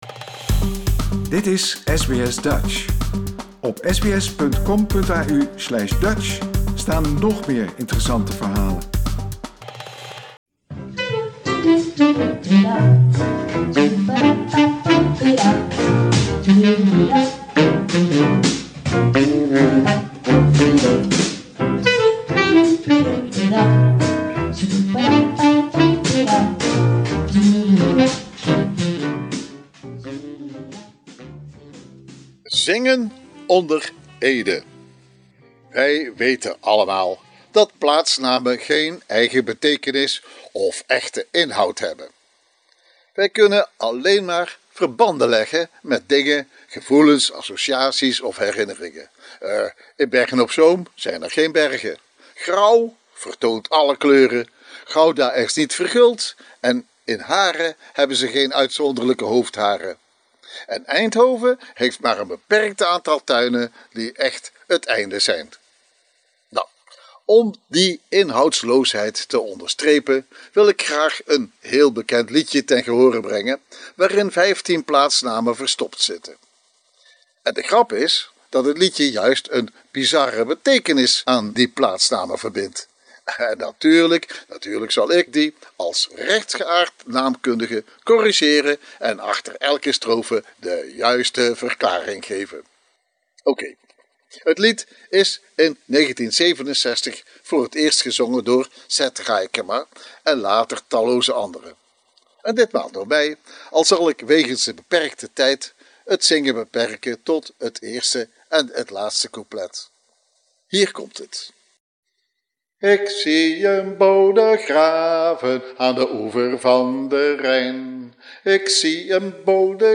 vertelt ons tussen het zingen door over de echte afkomst van die geestige maar verkeerde verzinsels.